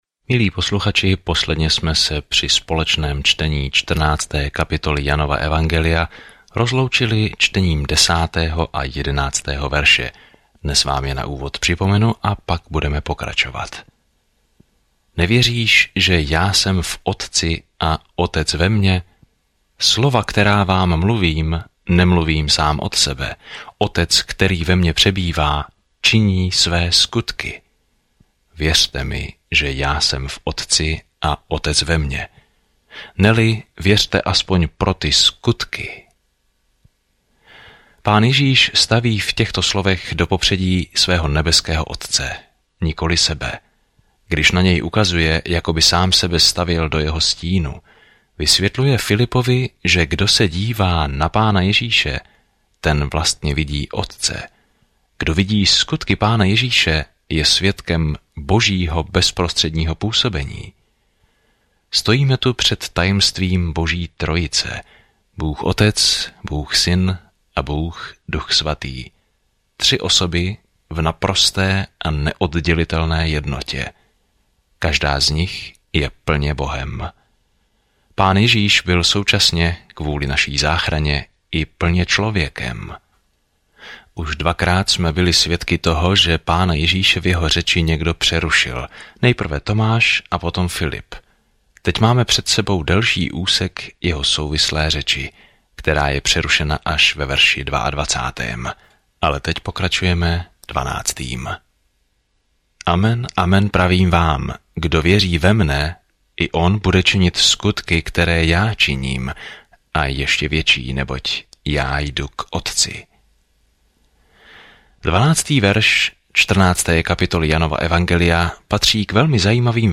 Písmo Jan 14:10-31 Den 31 Začít tento plán Den 33 O tomto plánu Dobrá zpráva, kterou Jan vysvětluje, je jedinečná od ostatních evangelií a zaměřuje se na to, proč bychom měli věřit v Ježíše Krista a jak žít v tomto jménu. Denně procházejte Janem a poslouchejte audiostudii a čtěte vybrané verše z Božího slova.